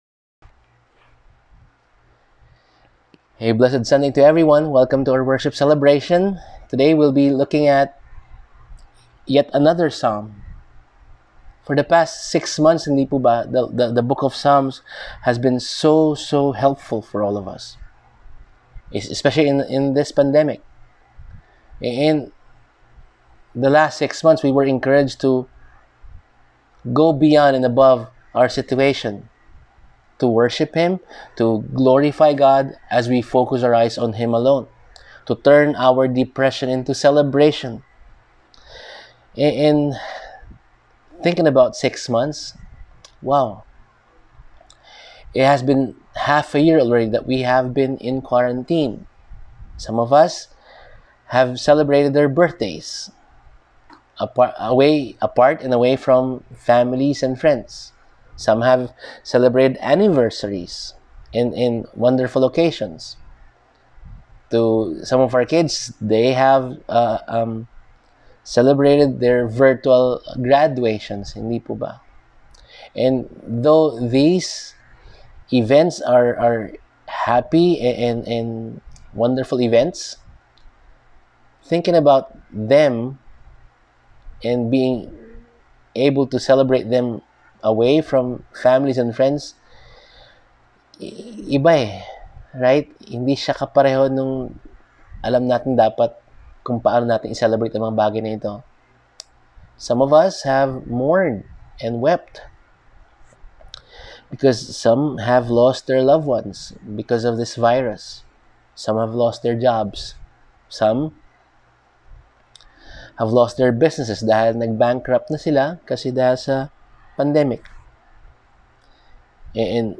Sunday Sermon Outline